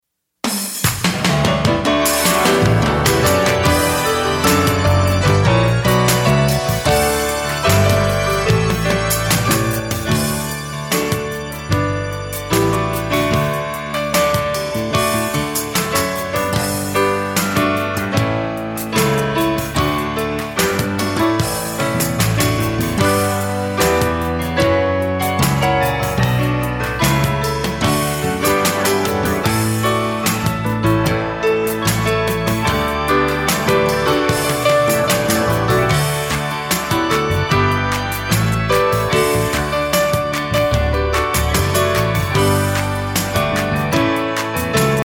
Listen to a sample of the instrumental.
Downloadable Instrumental Track